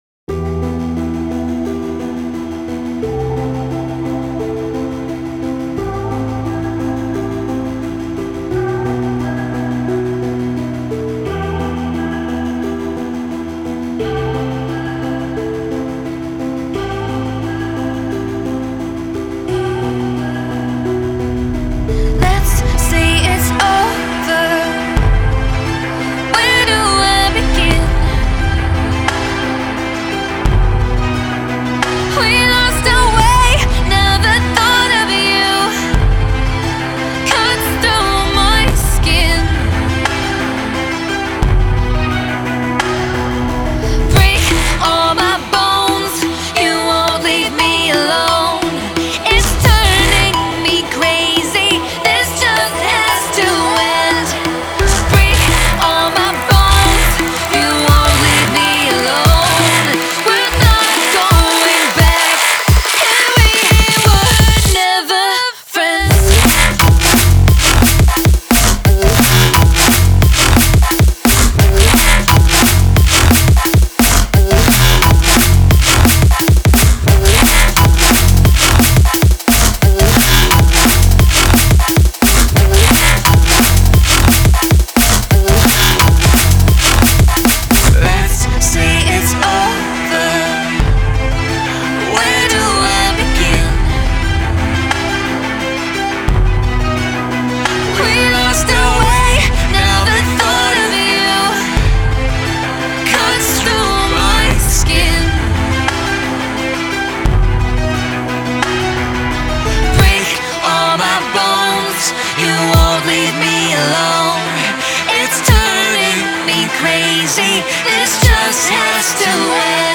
Genre:Drum and Bass
デモサウンドはコチラ↓